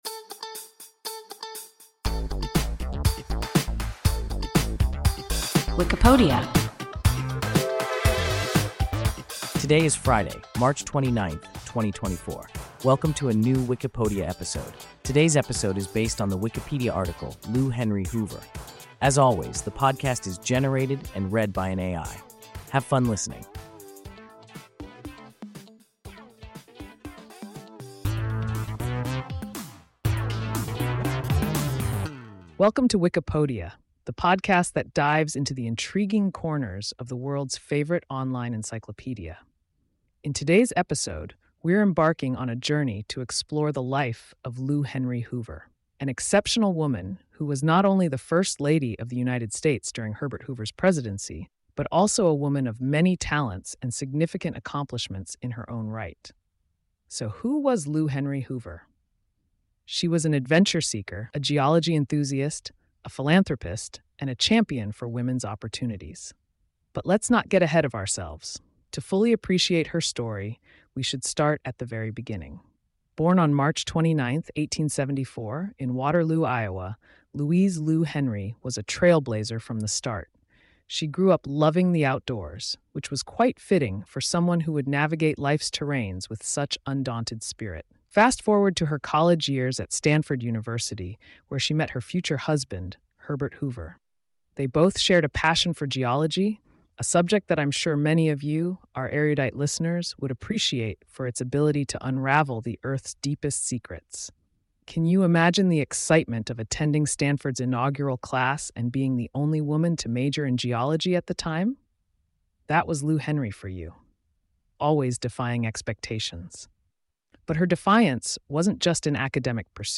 Lou Henry Hoover – WIKIPODIA – ein KI Podcast